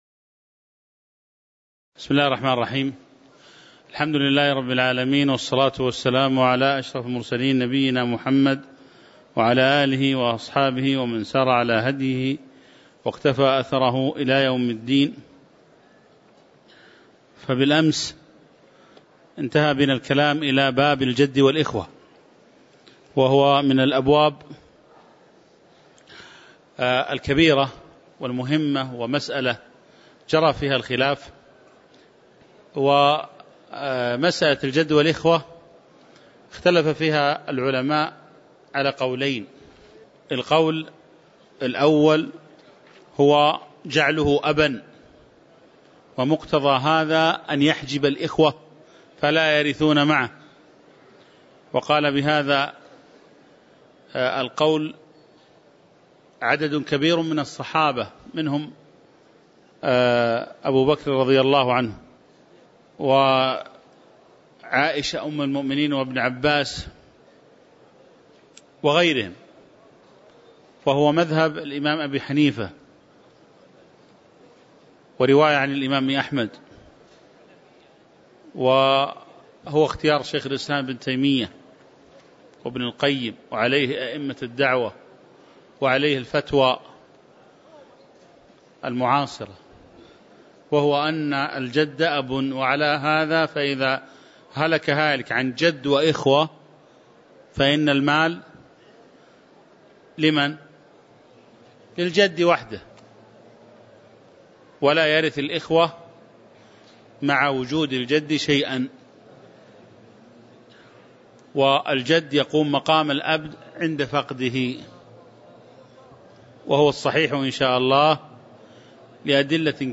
تاريخ النشر ١٣ جمادى الأولى ١٤٤١ هـ المكان: المسجد النبوي الشيخ